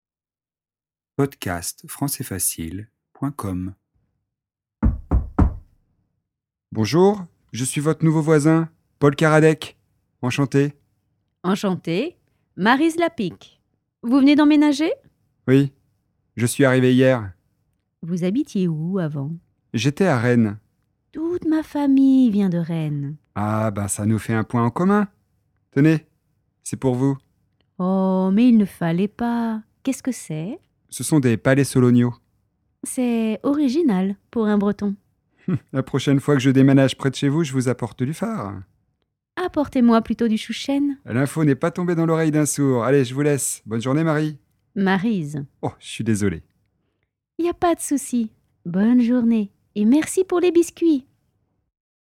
DIALOGUE :
nouveau_voisin_dialogue_fle.mp3